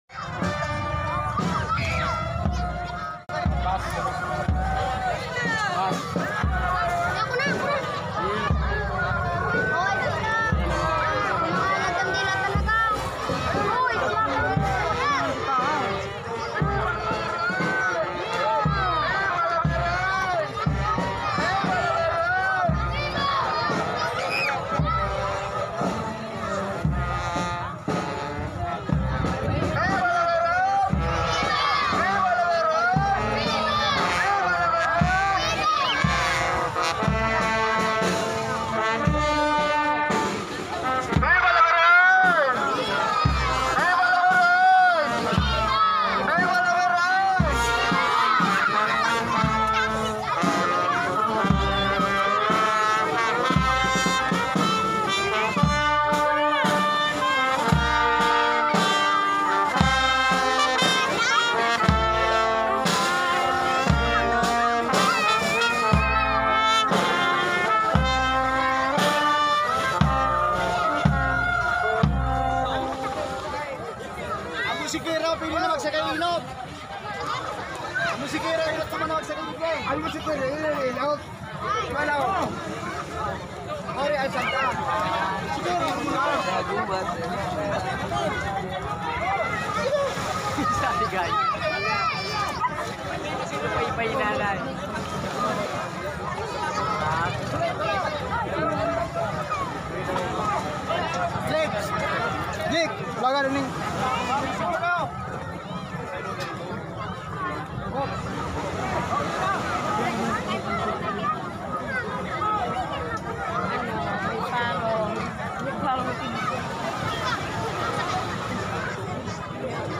alas Mp3 Sound Effect October 7, 2024 (Lunes) alas 4:00 nin hapon nangyari Traslacion and Fluvial Procession ni Virgen de Pen̈afrancia asin Divino Rostro sa Barangay Tagas, San Jose.